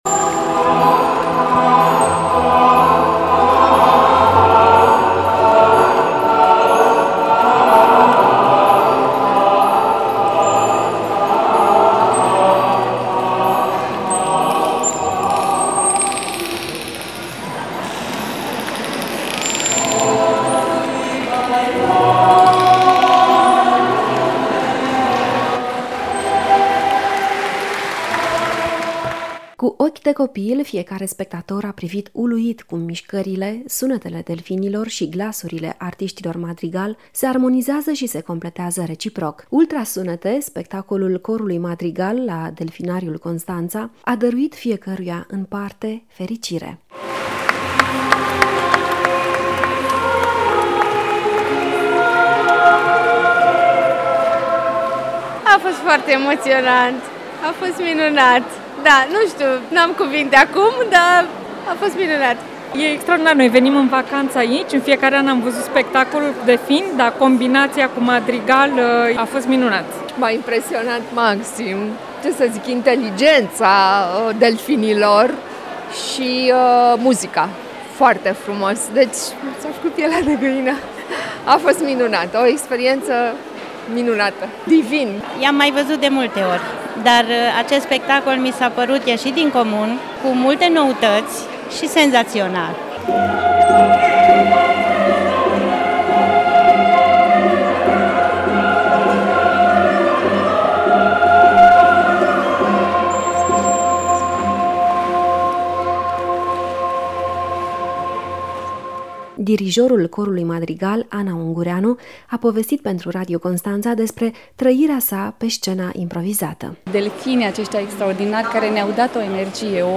Urmează un reportaj